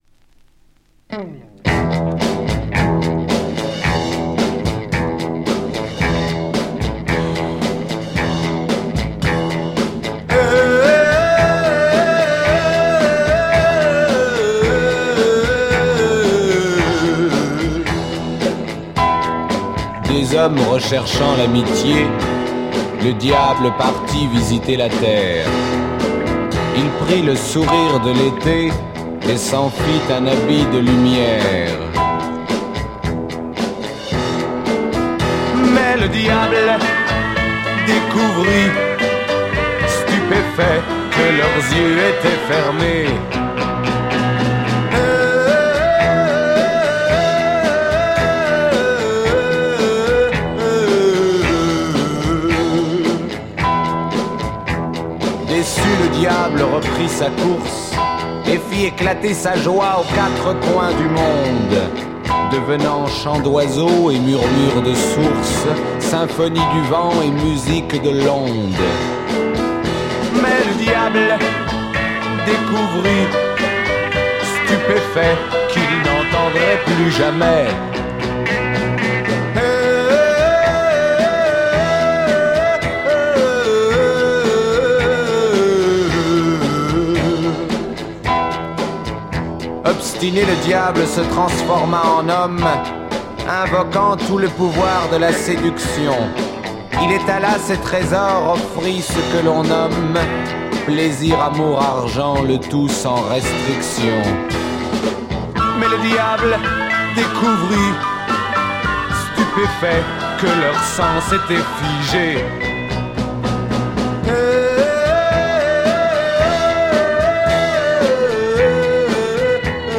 Great French Beat chanson album
quite underrate French Garage folk chanson album